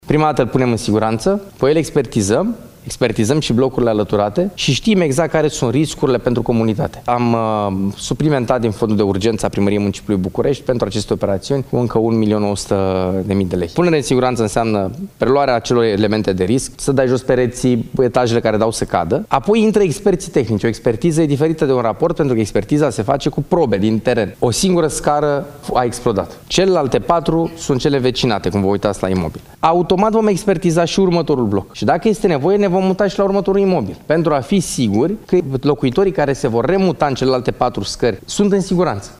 Edilul Stelian Bujduveanu a explicat și ce înseamnă punerea în siguranță a clădirii, în urma exploziei